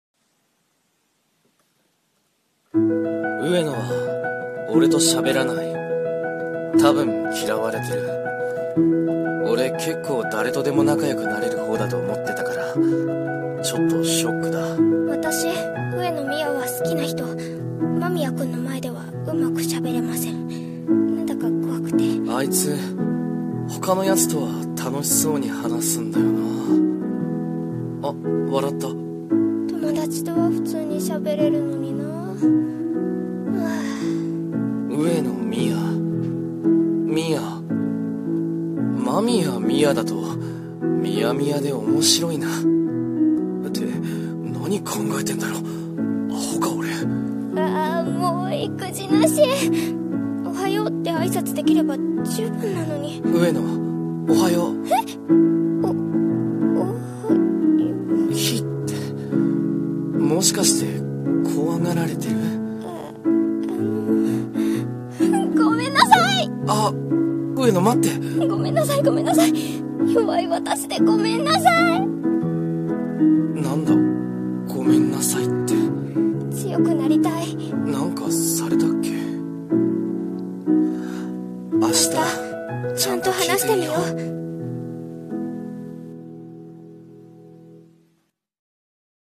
【２人声劇】